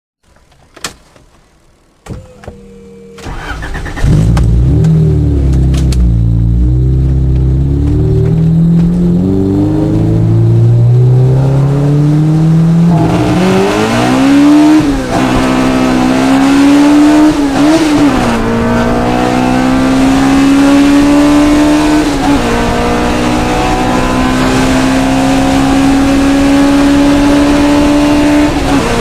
Honda Civic Vtec-sound-HIingtone
honda-civic-vtec_25158.mp3